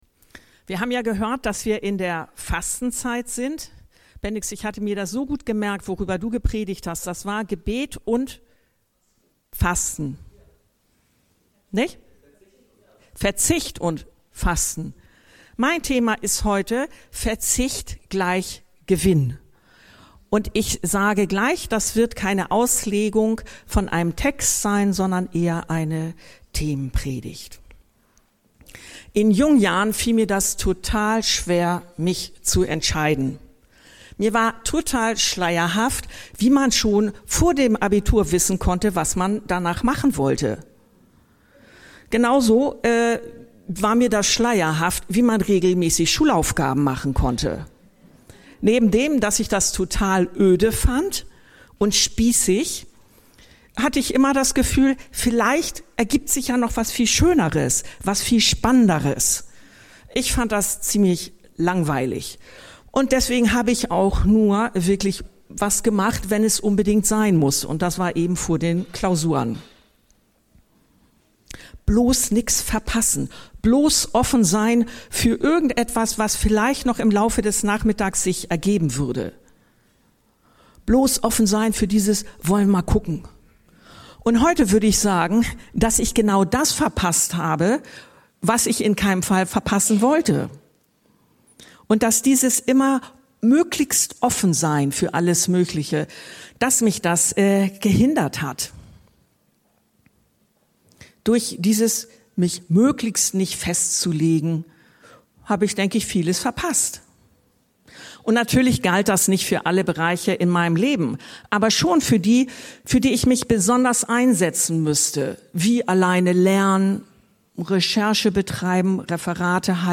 Verzicht ist gleich Gewinn ~ Anskar-Kirche Hamburg- Predigten Podcast